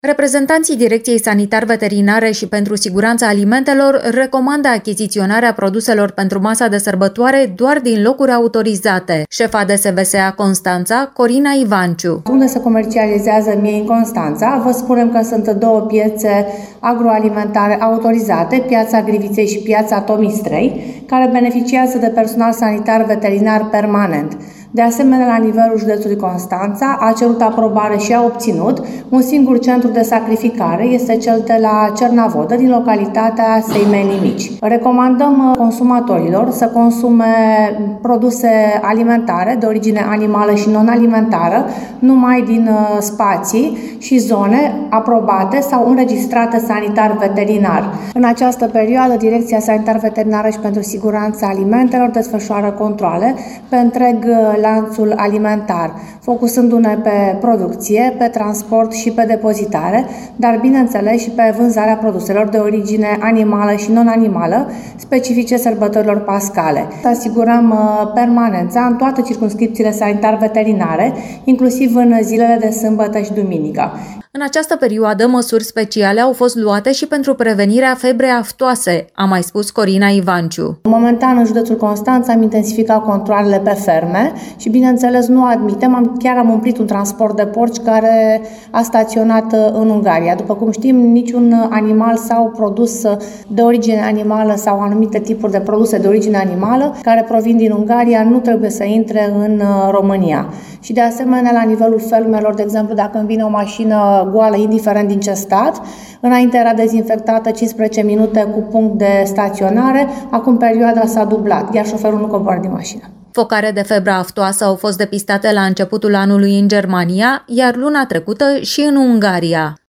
Datele au fost prezentate în ședința Colegiului Prefectural de șefa DSVSA Constanța, Corina Ivanciu, care a vorbit și despre intensificarea controalelor și măsurilor pentru prevenirea febrei aftoase.